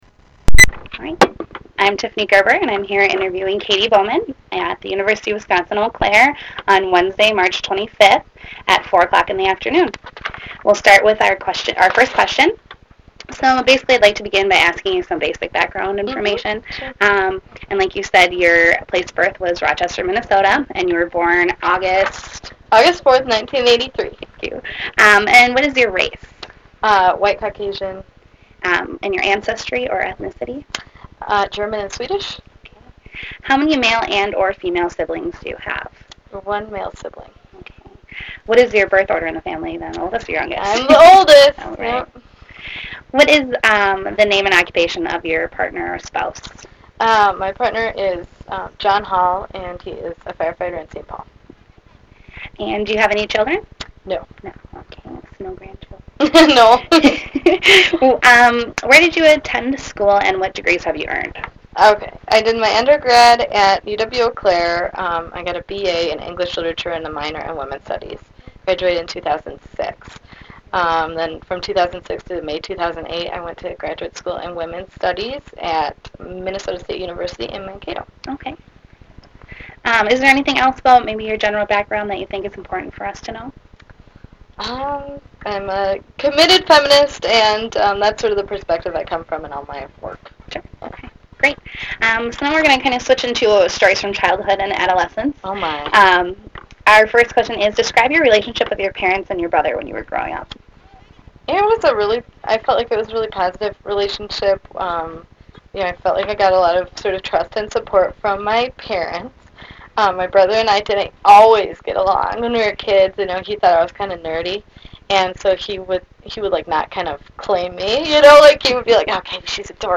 This interview is part of an oral history project conducted in honor of the 25th anniversary of the Women's Studies Program at the University of Wisconsin - Eau Claire.